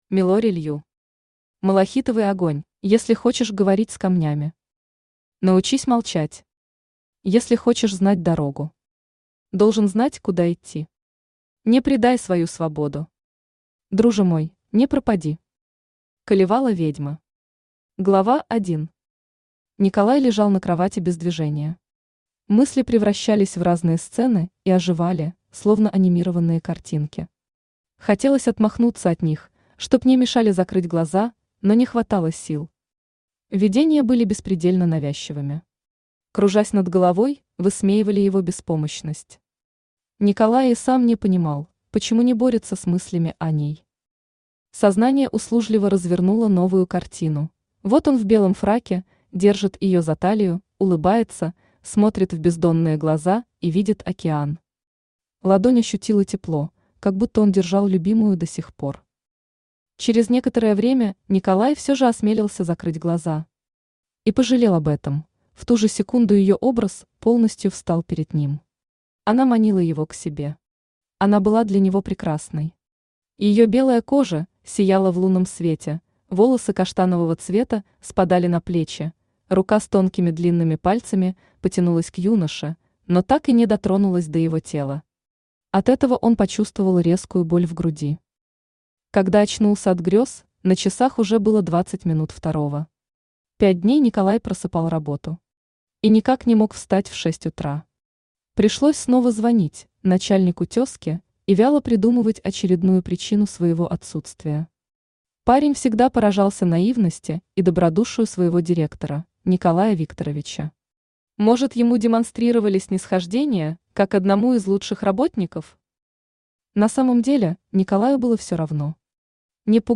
Аудиокнига Малахитовый огонь | Библиотека аудиокниг
Aудиокнига Малахитовый огонь Автор Милори Лью Читает аудиокнигу Авточтец ЛитРес.